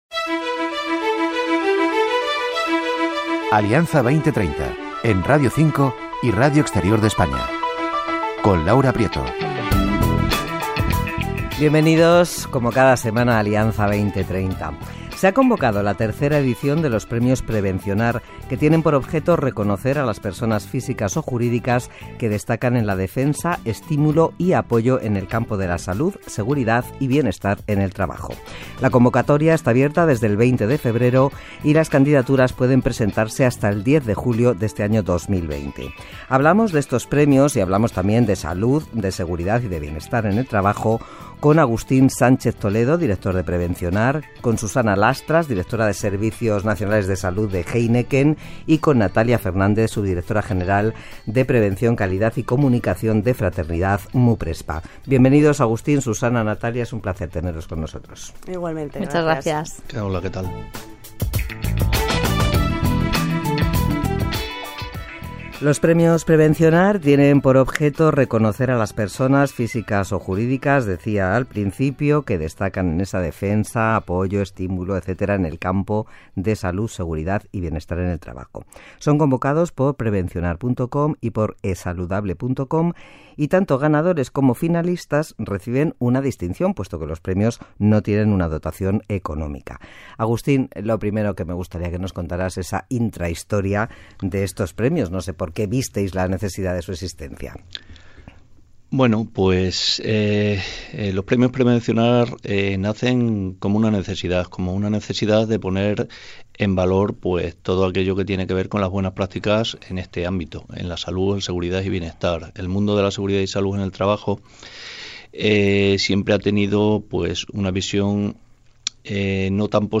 RNE Alianza 2030 entrevista